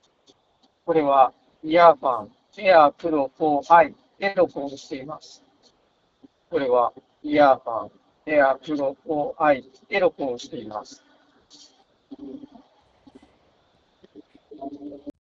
雑音を抑える効果はかなり高め。
試しにスピーカーから雑踏音を大きなボリュームで流しながらマイクで収録した音声がこちら。雑音が殆ど感じられないことが分かります。
ただし、僕が試した限りではマイクの性能がイマイチ・・・。